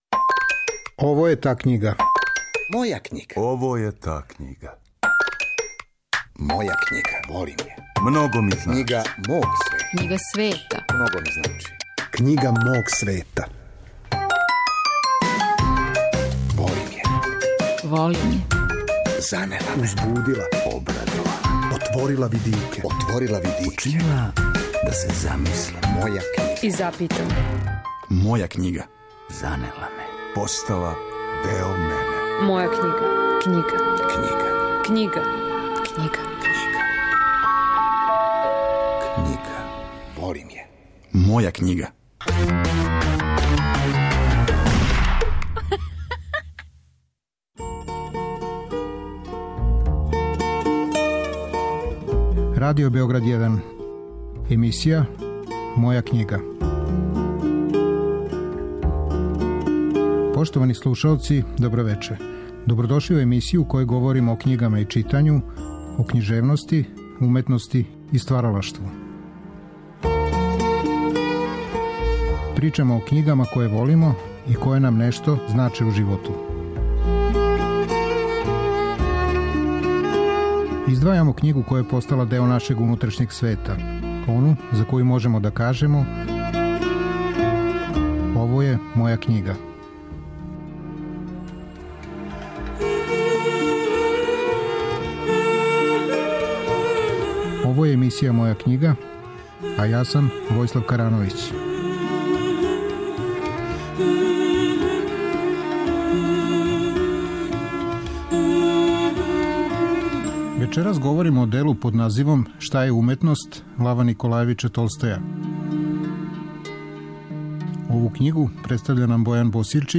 Реприза